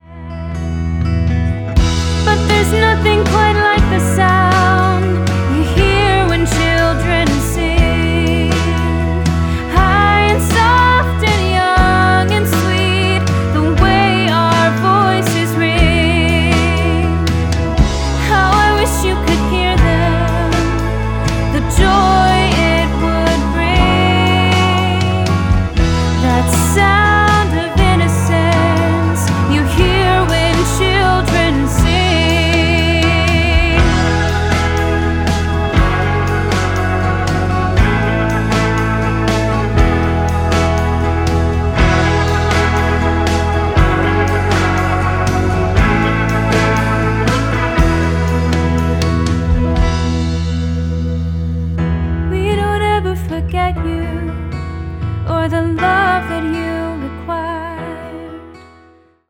a wonderful young singer